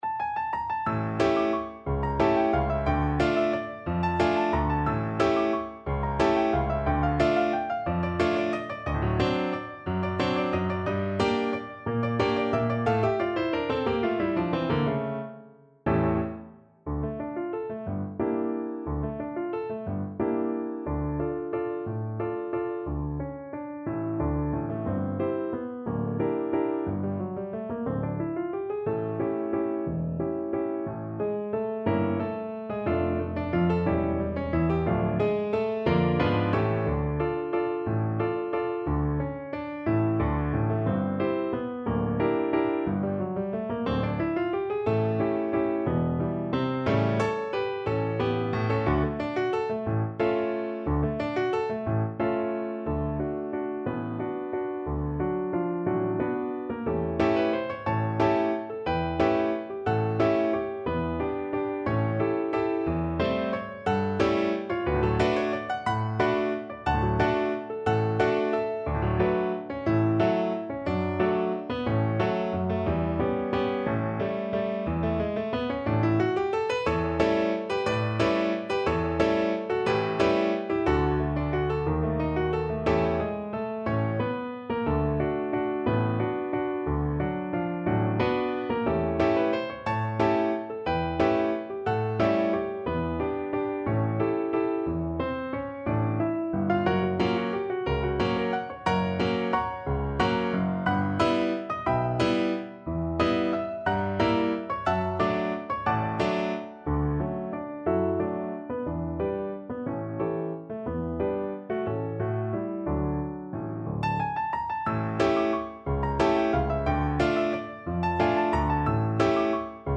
3/4 (View more 3/4 Music)
Allegro movido =180 (View more music marked Allegro)